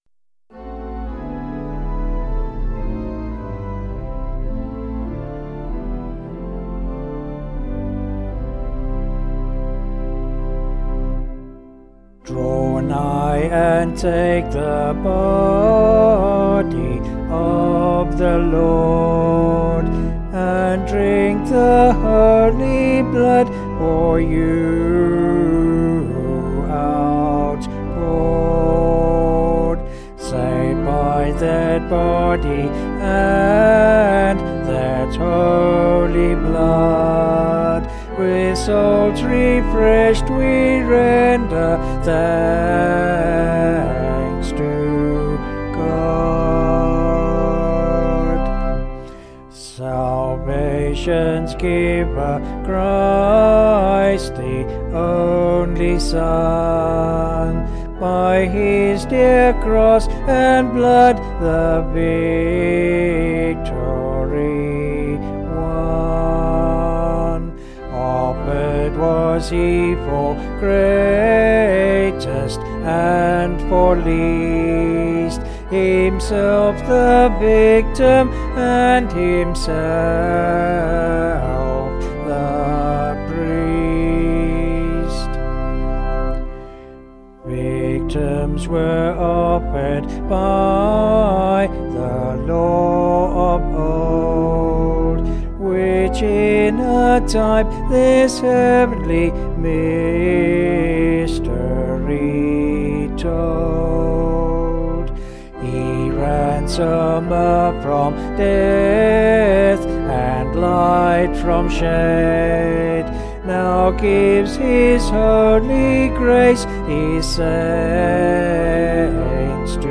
Vocals and Organ   264.1kb Sung Lyrics